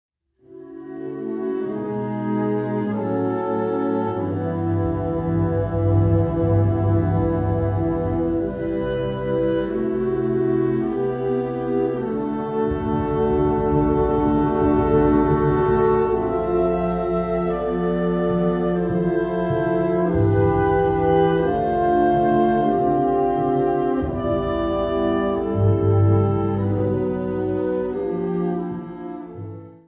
Subcategorie Hedendaagse blaasmuziek (1945-heden)
Bezetting Ha (harmonieorkest); CB (Concert Band)